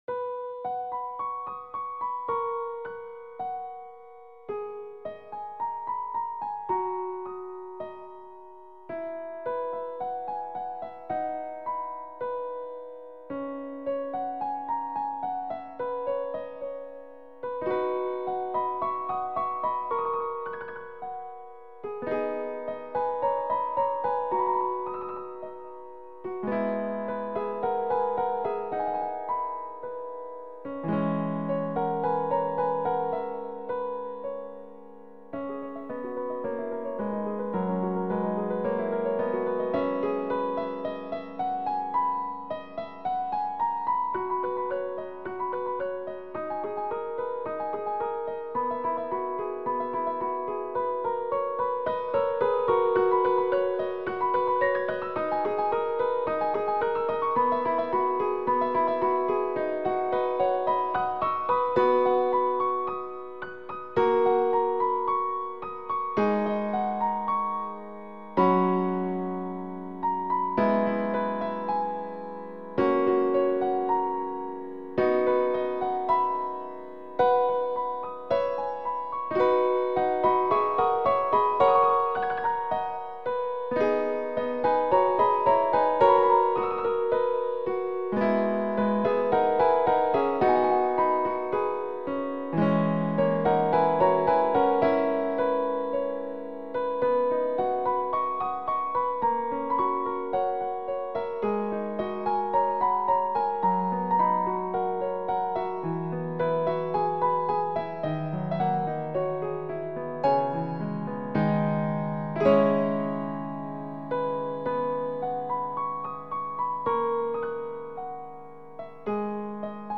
全体的に少し高音寄りで雰囲気は柔らかく、編曲において少し悩みました。
何せ雰囲気が結構変わってますしね。